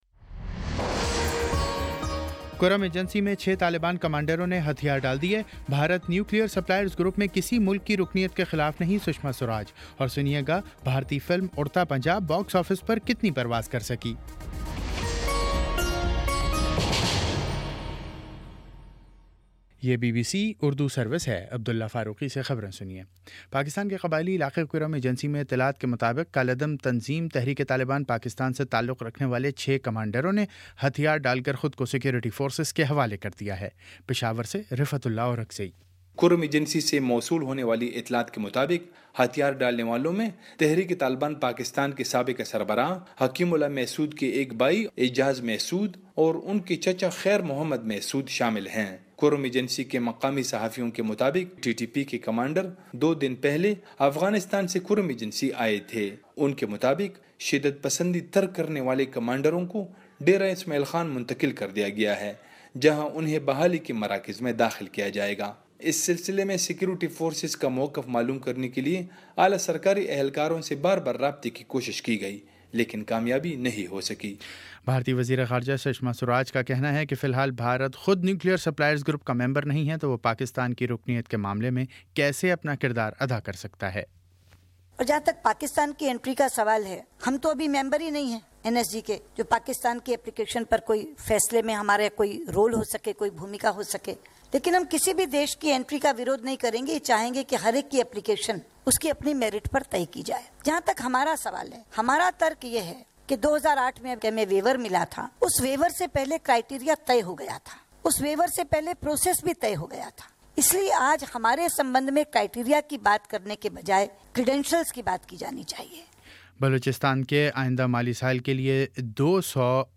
جون 19 : شام سات بجے کا نیوز بُلیٹن